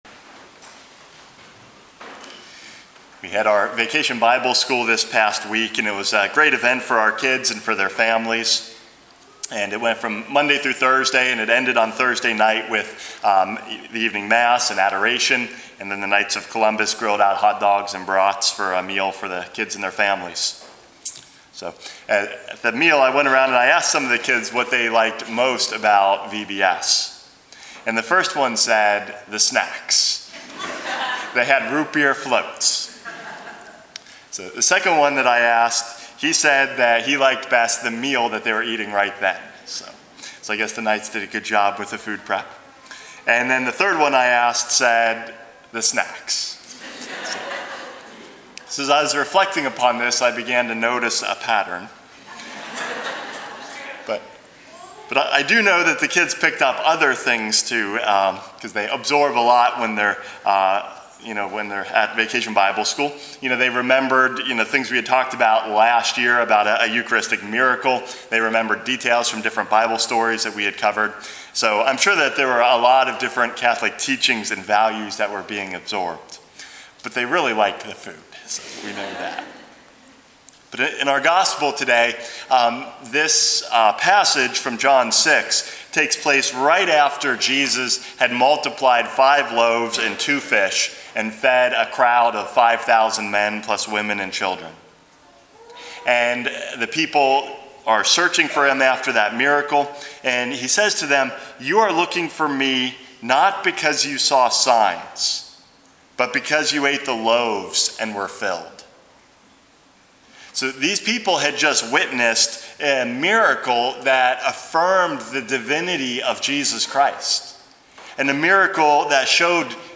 Homily #408 - Food that does not Perish